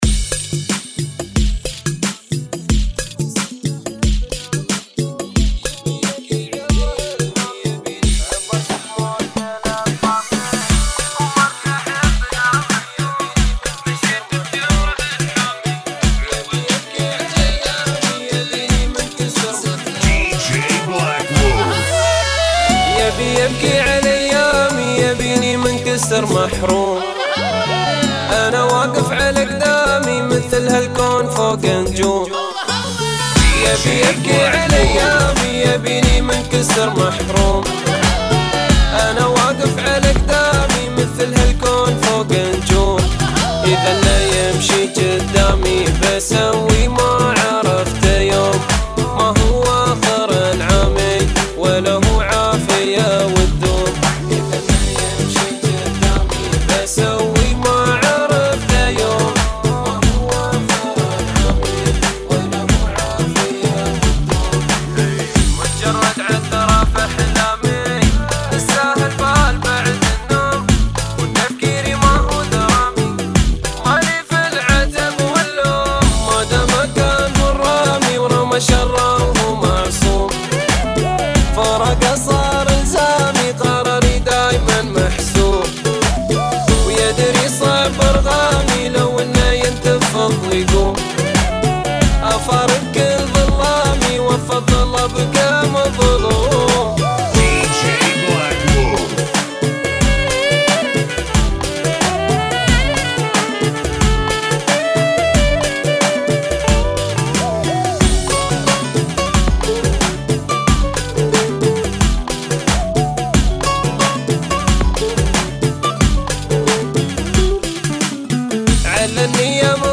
90 Bpm